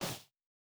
Bare Step Snow Hard A.wav